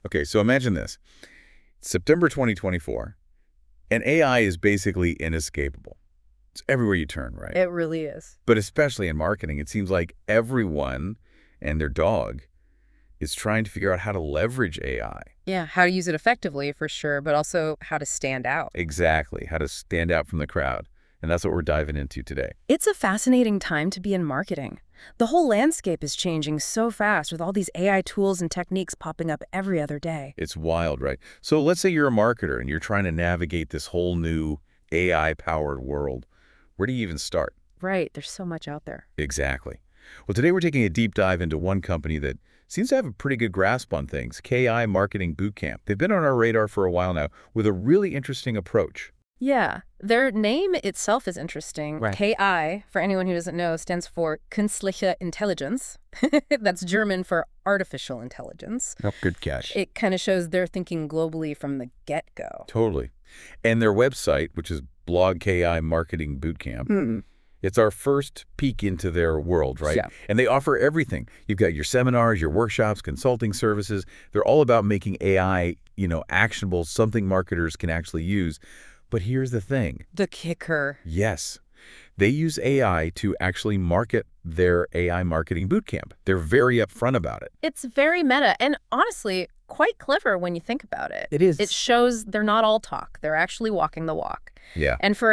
Die neueste Magie: Audio-Unterhaltungen!
Stell dir vor, du könntest zwei KI-Experten dabei zuhören, wie sie über deine Marketing-Strategien diskutieren.
Die KI-Stimmen unterhielten sich auf Englisch über KI im Marketing, und an einer Stelle sagte eine der Stimmen: „Künstliche Intelligenz – this is German and means artificial intelligence. Haha!“ Es ist faszinierend, wie natürlich und unterhaltsam diese generierten Gespräche klingen können.